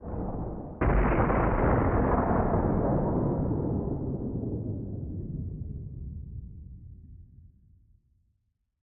Minecraft Version Minecraft Version latest Latest Release | Latest Snapshot latest / assets / minecraft / sounds / ambient / nether / nether_wastes / mood1.ogg Compare With Compare With Latest Release | Latest Snapshot